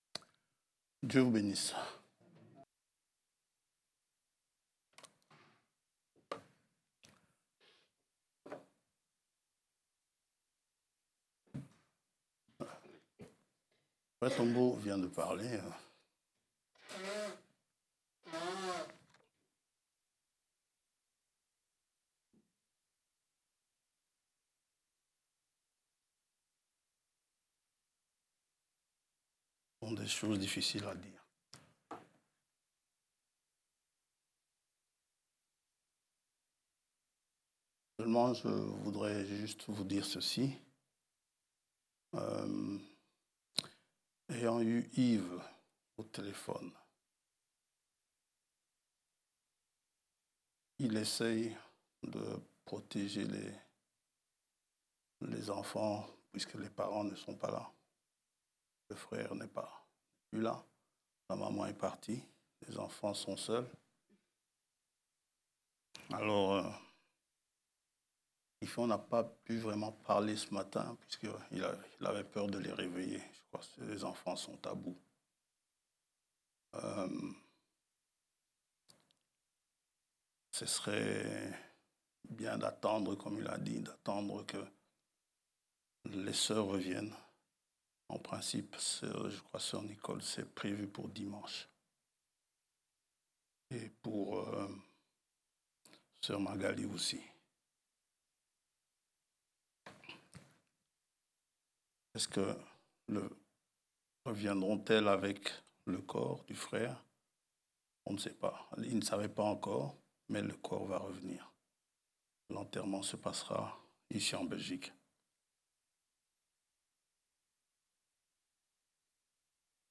Prédications